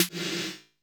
SFX S808.wav